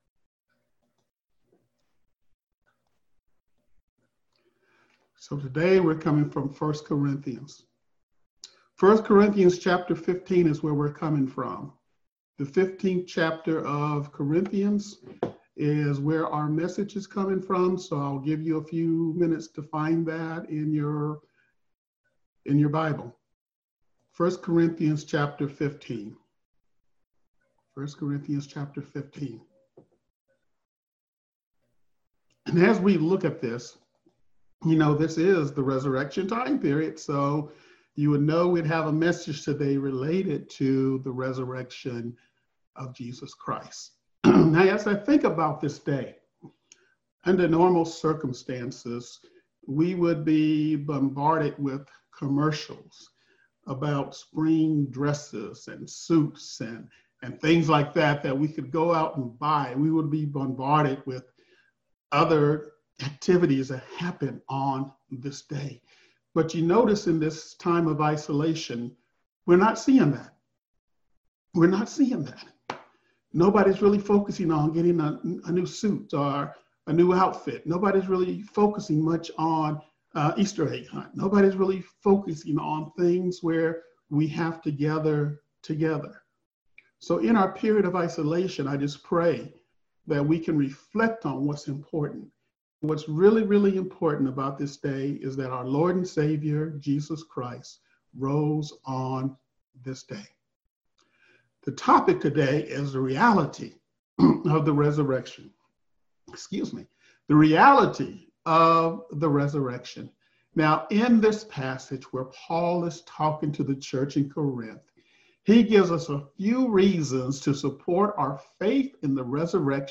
RESURRECTION DAY VIRTUAL SERVICE - Beacon Light Baptist Church